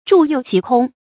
杼柚其空 zhù yòu qí kōng
杼柚其空发音